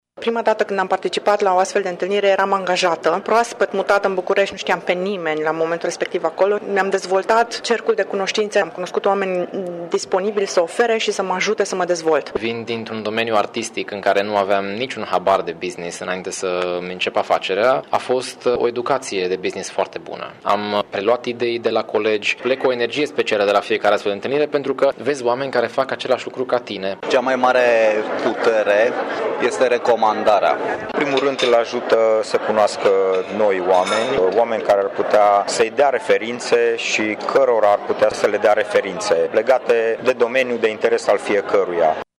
Antreprenorii sunt de părere că relaționarea este foarte importantă în afaceri: